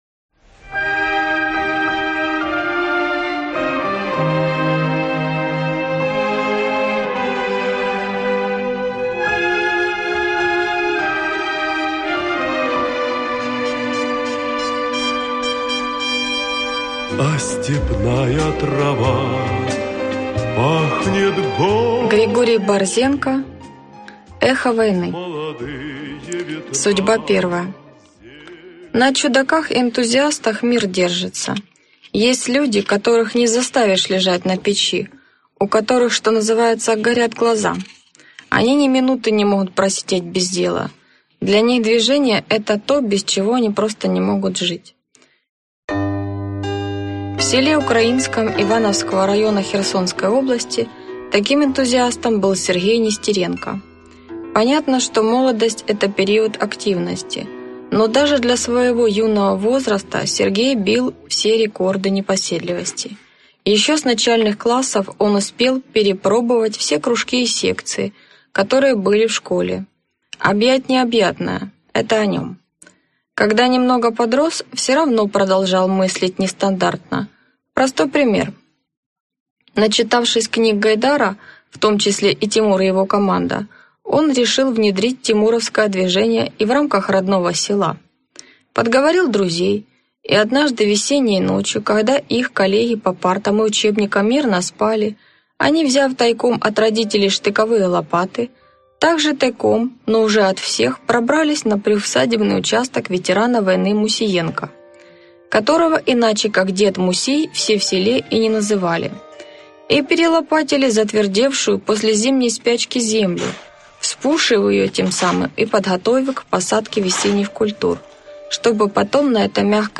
Аудиокнига Эхо войны | Библиотека аудиокниг
Прослушать и бесплатно скачать фрагмент аудиокниги